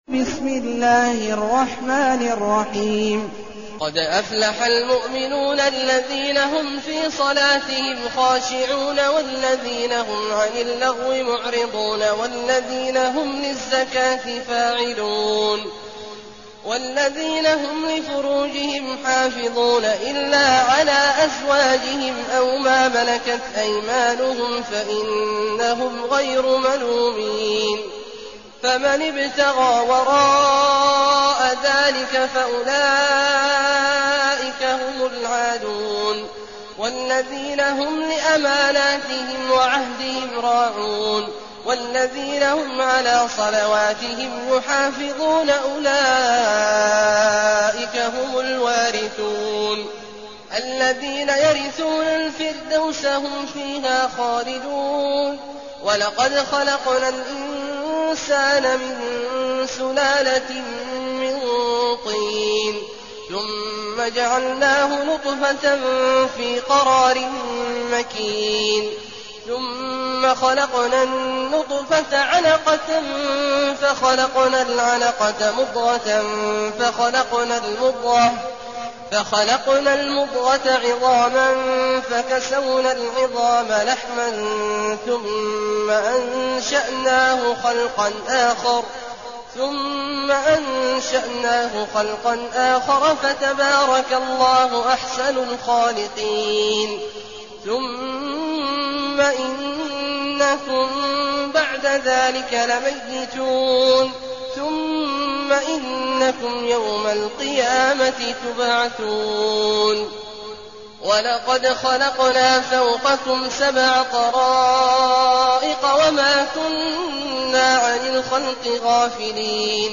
المكان: المسجد النبوي الشيخ: فضيلة الشيخ عبدالله الجهني فضيلة الشيخ عبدالله الجهني المؤمنون